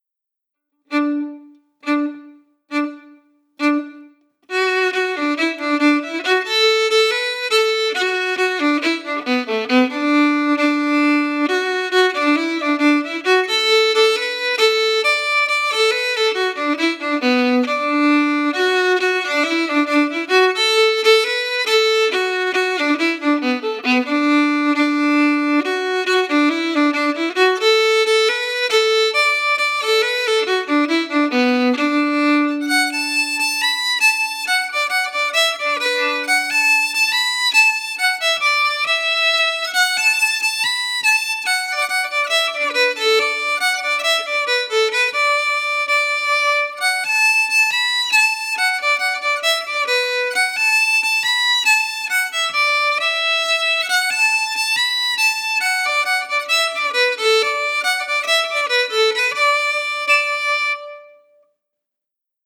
Key: D
Form: Reel
MP3: (Played slowly for learning by the composer)
Genre/Style: “Pseudo-Appalachian”, according to the composer